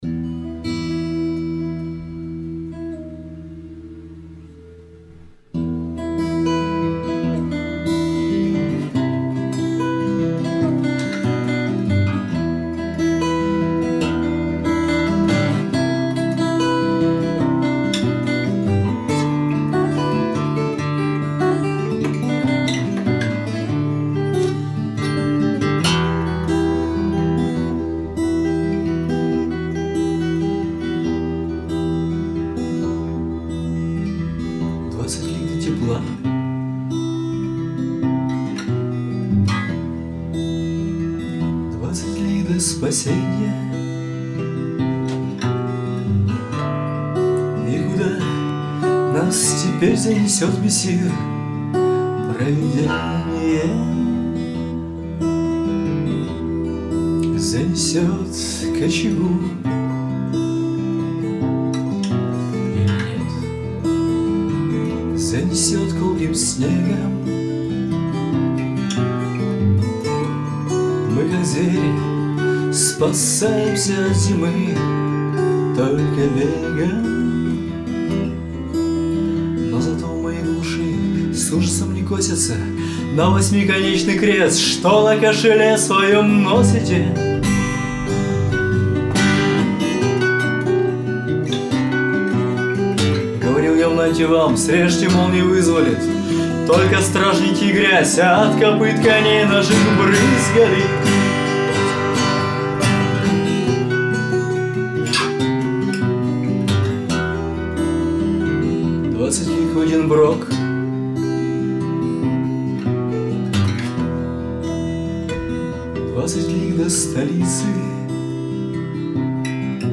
NB! Здесь представлены не студийные, а живые записи!